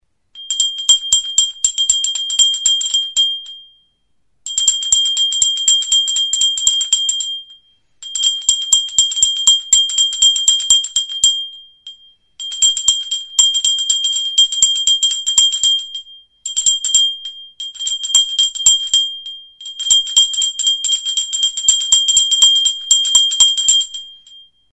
Music instrumentsEZKILATXOA; CAMPANILLA DE BARRO
Idiophones -> Struck -> Indirectly
Recorded with this music instrument.
Buztinezko ezkilatxoa da. Mihia ere buztinezkoa du.
CLAY; CERAMICS